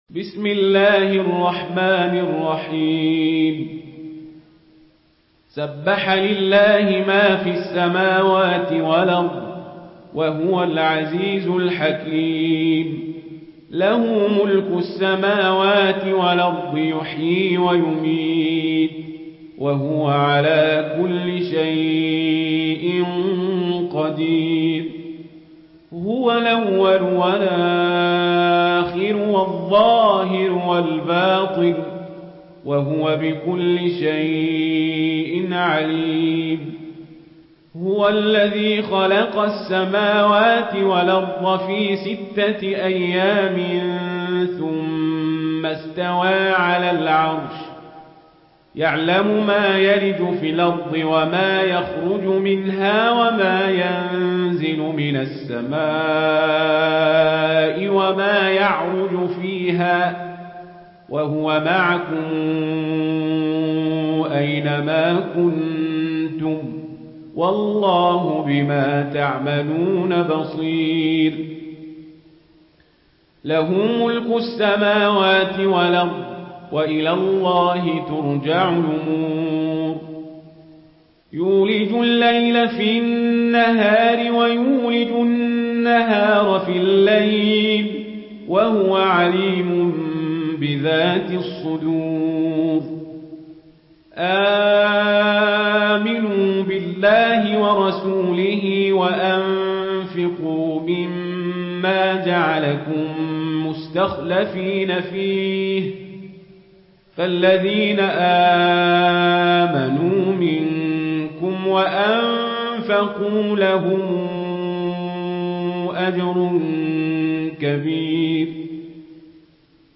سورة الحديد MP3 بصوت عمر القزابري برواية ورش
مرتل ورش عن نافع